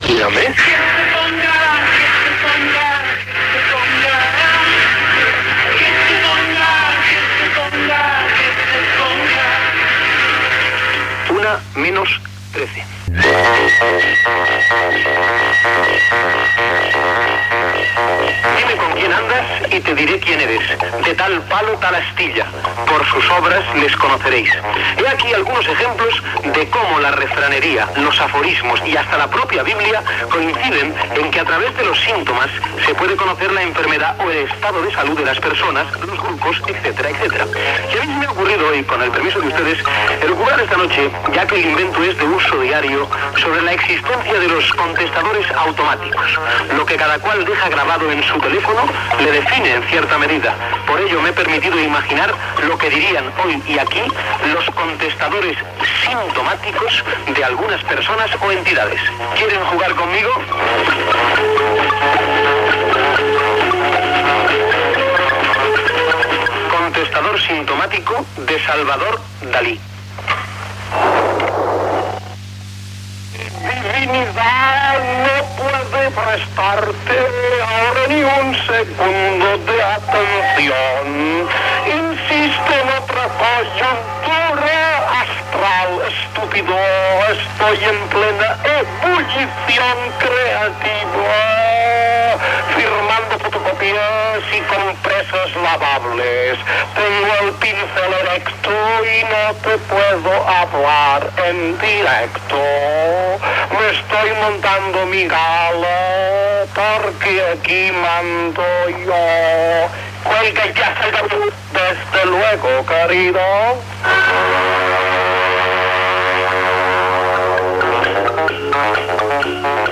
Identificació del programa, hora, el joc dels contestadors automàtics (simtomàtics) de Dalí, José Manuel Lara, María Jiménez, Ronald Reagan, Josep Tarradellas, etc. Gènere radiofònic Entreteniment Presentador/a Ruiz, Pedro